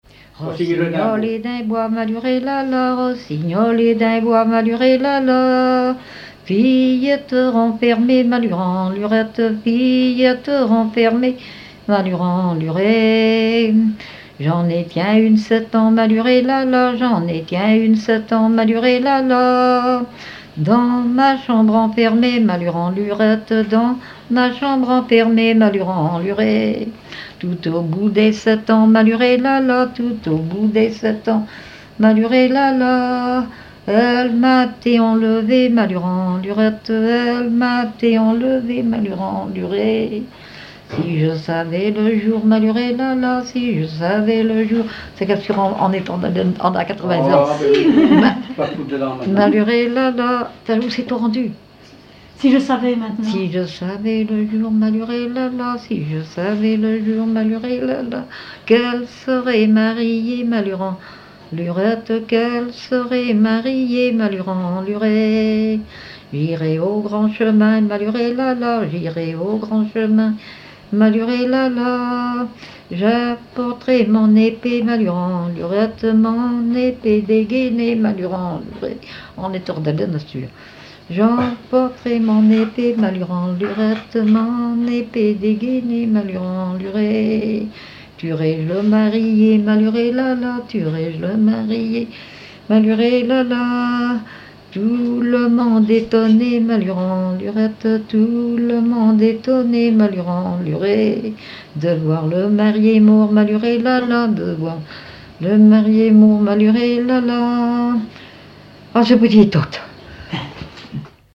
Localisation Noirmoutier-en-l'Île (Plus d'informations sur Wikipedia)
Genre laisse
Catégorie Pièce musicale inédite